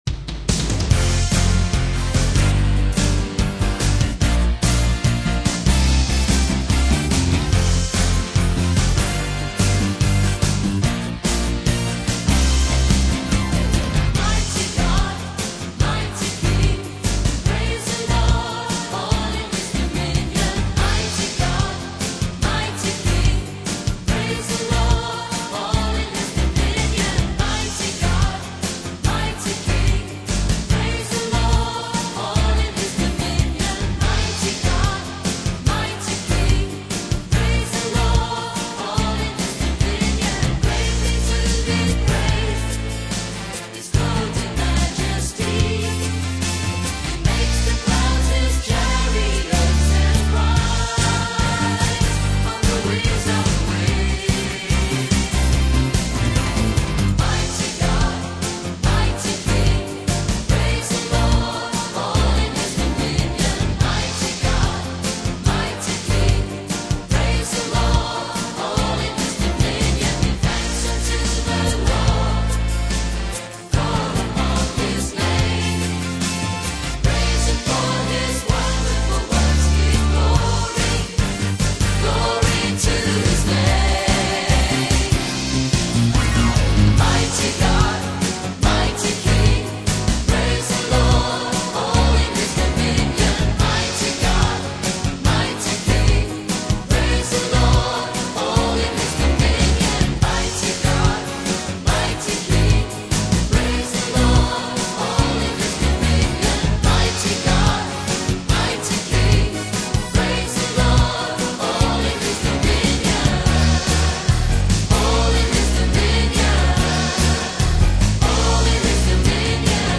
Solid exhortation